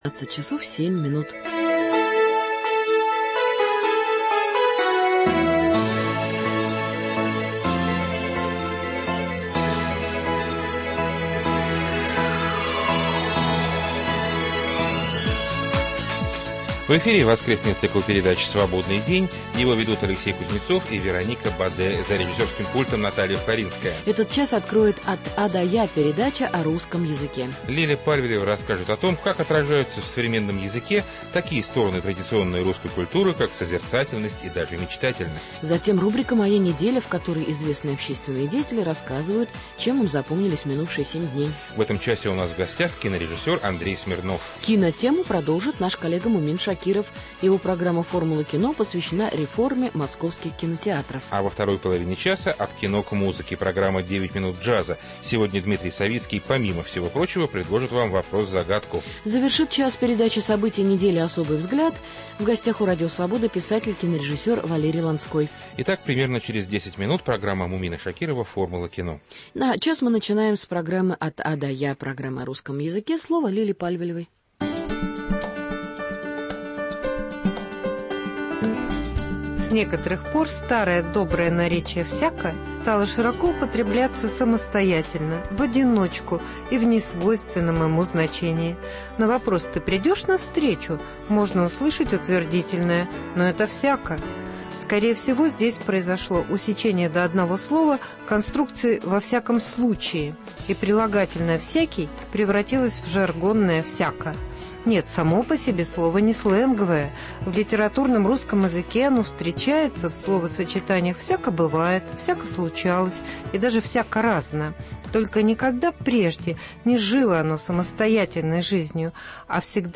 После выпуска новостей - "От А до Я" – передача о русском языке" с рассказом о том, о том, как отражаются в современном языке такие стороны традиционной русской культуры, как созерцательность и даже мечтательность. Затем программа "Формула кино" с разговором о реформе московских кинотеатров. Во второй половине часа – программа "9 минут джаза", в которой вам будет предложена музыкальная загадка.